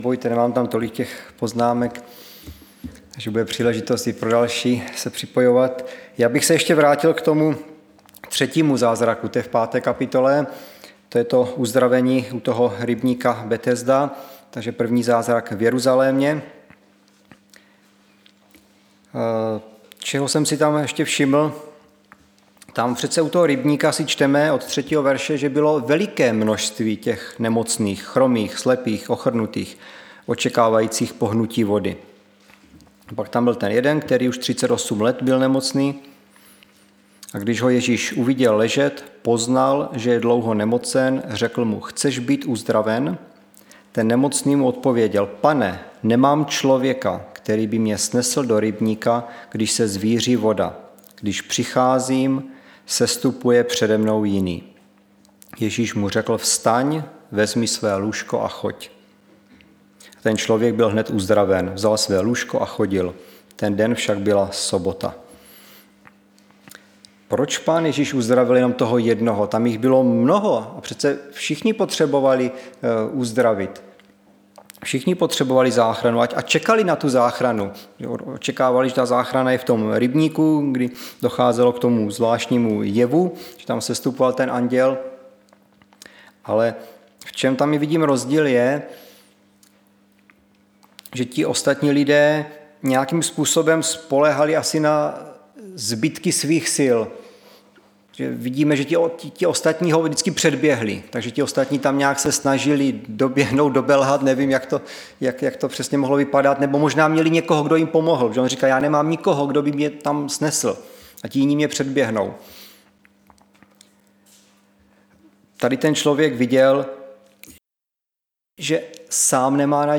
Středeční vyučování